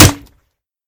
m203_grenshoot.ogg